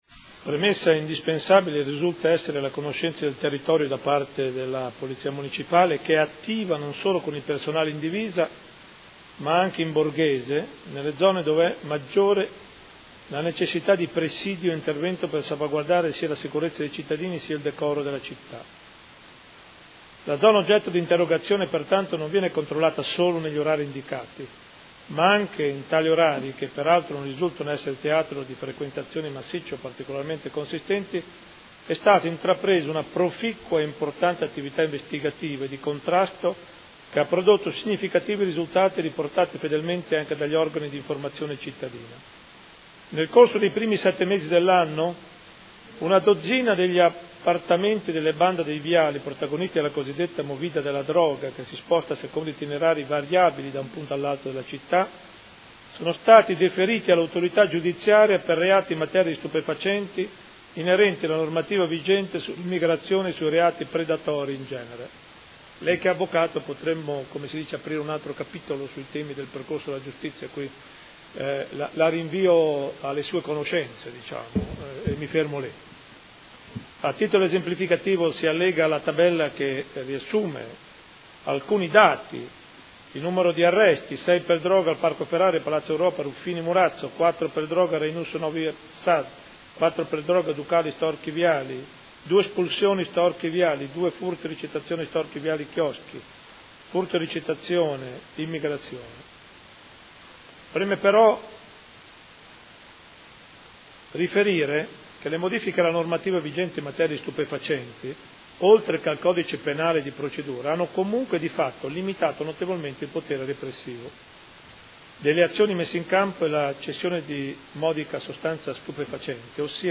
Seduta del 22 ottobre. Interrogazione del Consigliere Pellacani (F.I.) avente per oggetto: Il Signor Sindaco Muzzarelli non crede sia ora di intervenire per far cessare il vergognoso spettacolo quotidiano della movida della droga dietro al Teatro Storchi?. Risponde il sindaco